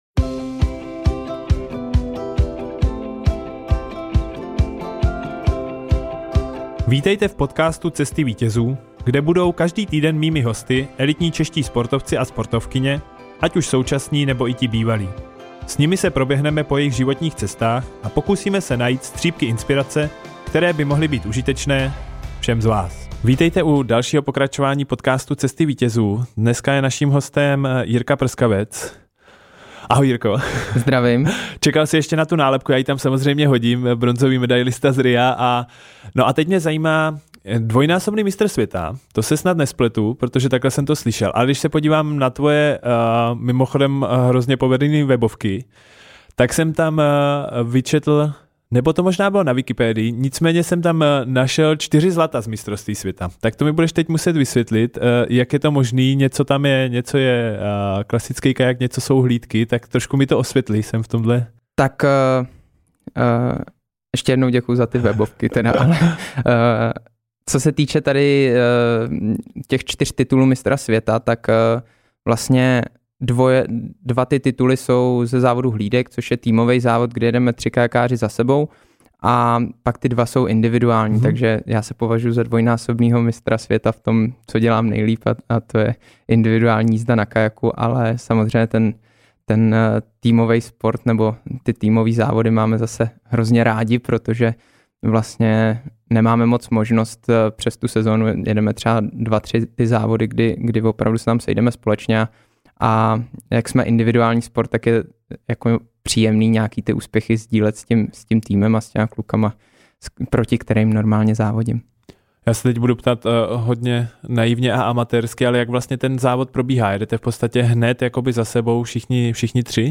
Hostem šťastného třináctého dílu byl vodní slalomář a bronzový olympijský medailista z Ria Jirka Prskavec. V moc příjemném rozhovoru jsme probrali, jak se dostává do svého “tunelu”, z čeho všeho jdou dělat video-rozbory a taky jak důležitá je pro něj rodina. Užijte si rozhovor!